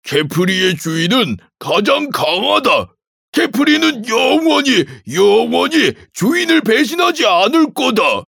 남자